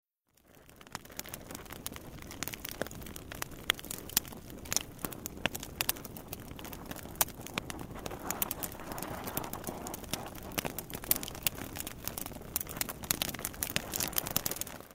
細火聲音效果